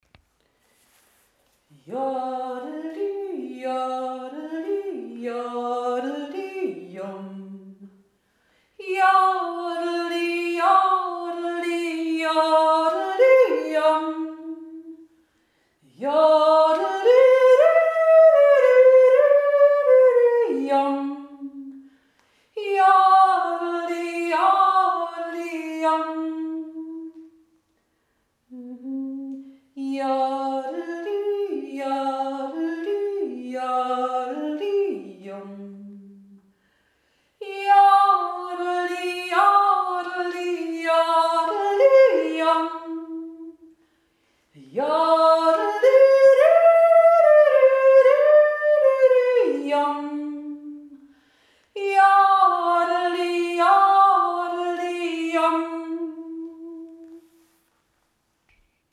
2. Stimme